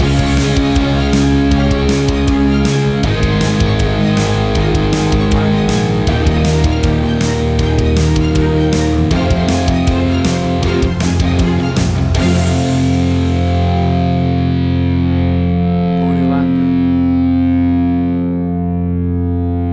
Classic punk rock sound with string orchestra.
WAV Sample Rate: 16-Bit stereo, 44.1 kHz
Tempo (BPM): 158